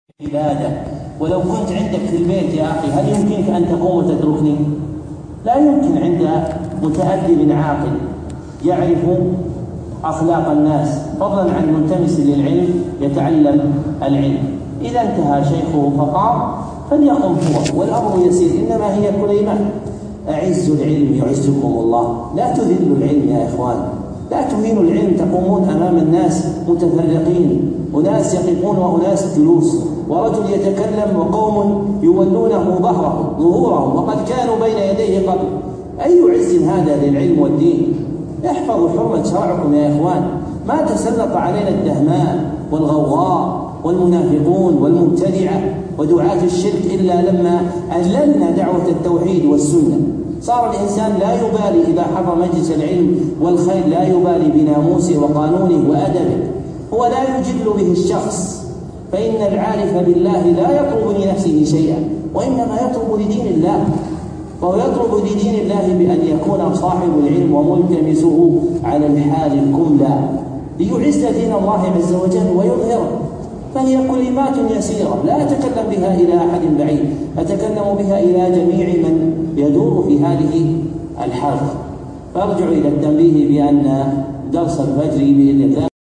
موعظة نافعة في أدب الدرس